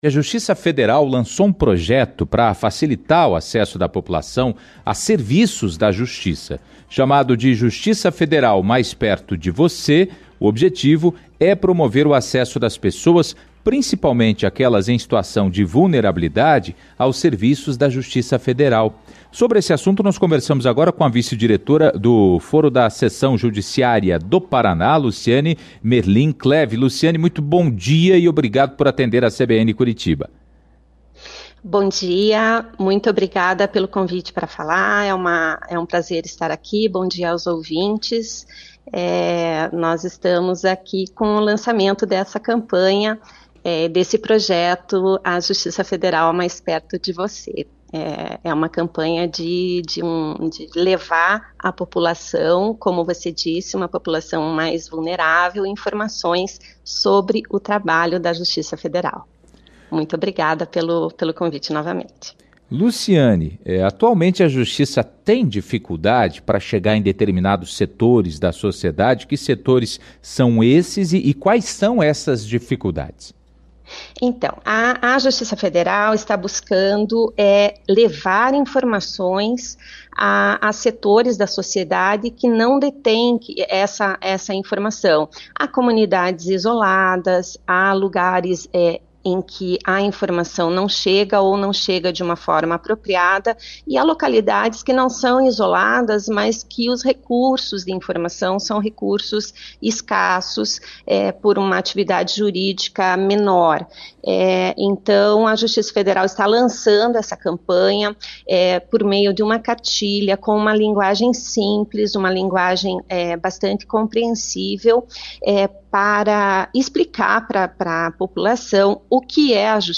Sobre esse assunto, nós conversamos com a vice-diretora do Foro da Seção Judiciária do Paraná, Luciane Merlin Clève. Ouça a entrevista dela no CBN Curitiba 1ª Edição de hoje (21):